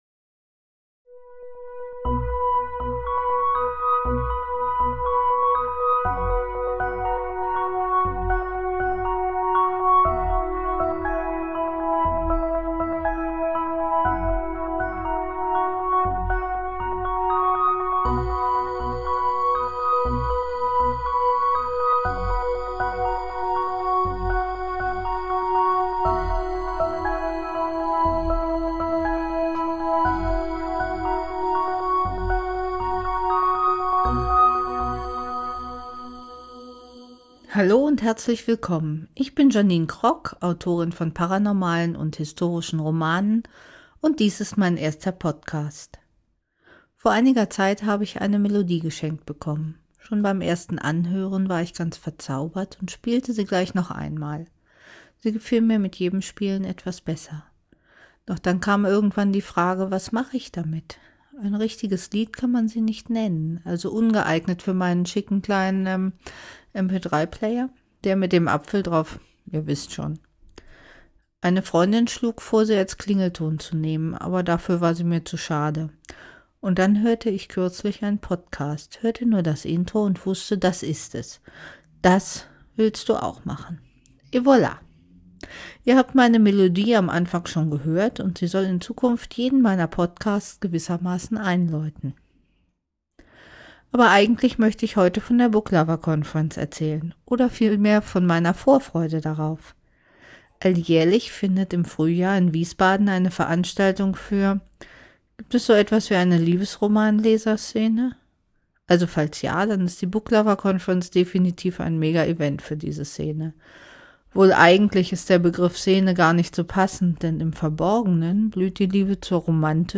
Niedlich und versehentlich auch mit unangekündigter Werbung, das war damals noch nicht strafbar.